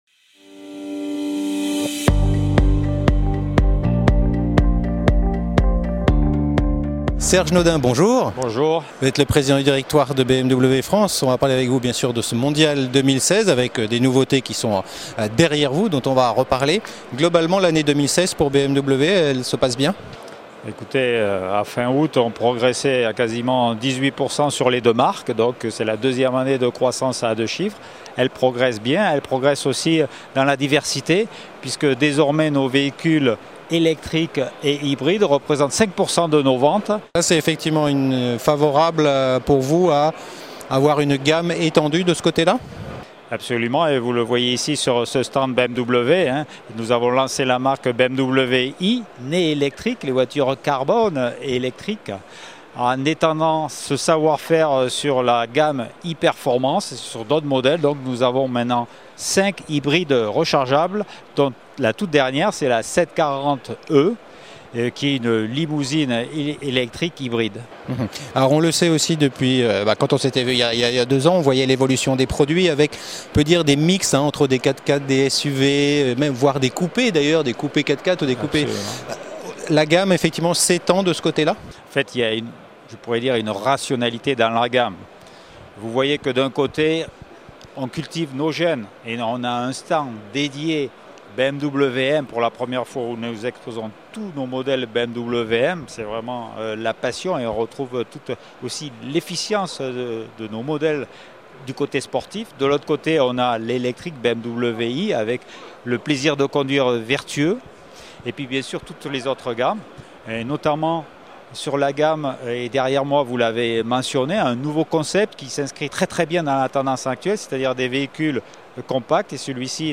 La Web Tv au Mondial de l'Automobile 2016
Category: L'INTERVIEW